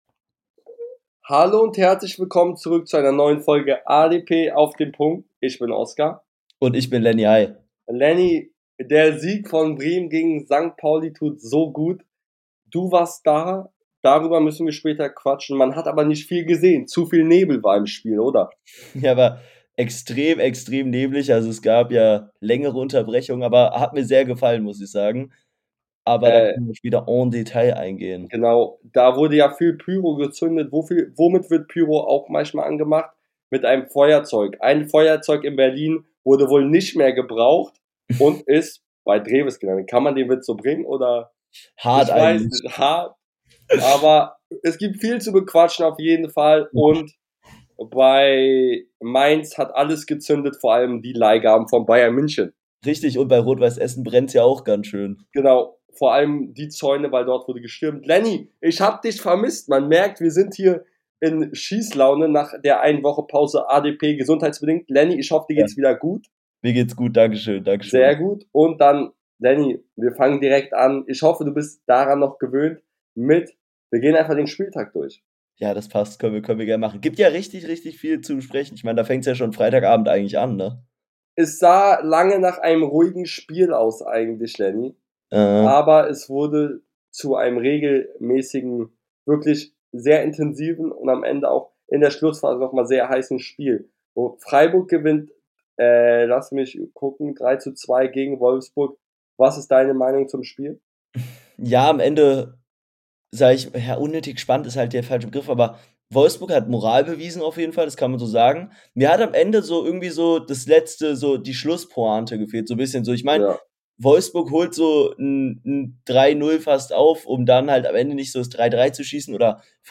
In der heutigen Folge zum Jahresabschluss reden die beiden Hosts über das Feuerzeug in Berlin , Wirtz Verlängerung , das brennende Haus in Dortmund und vieles mehr